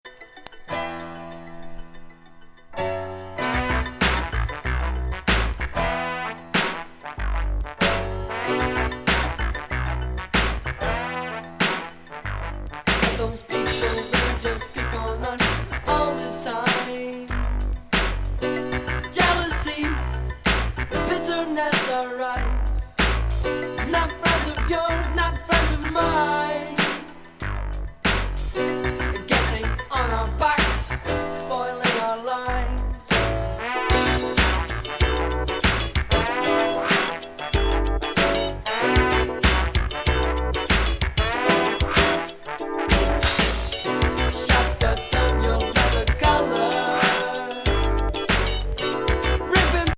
ロッキン・ギター・ブギー